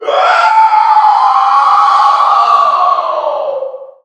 NPC_Creatures_Vocalisations_Puppet#6 (hunt_06).wav